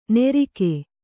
♪ nerike